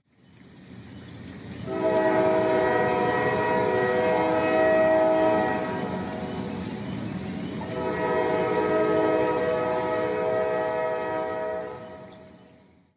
train 1
Tags: project personal sound effects